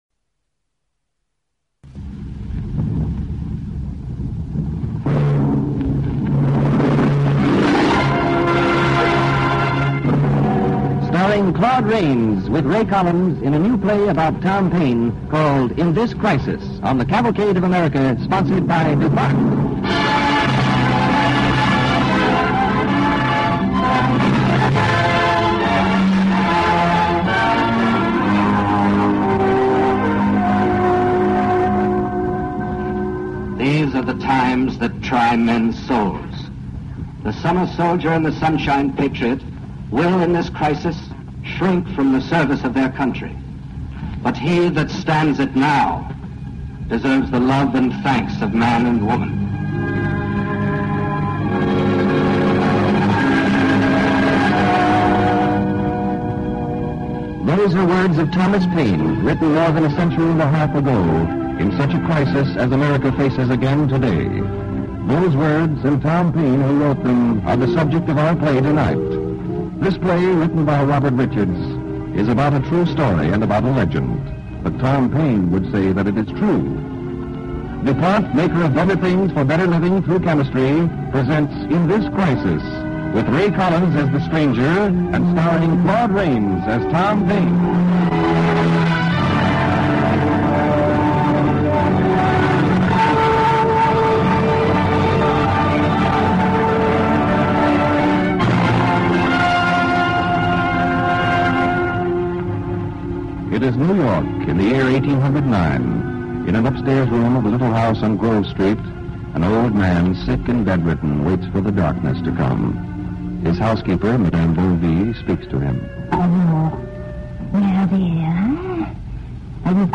starring Claude Rains and Agnes Moorehead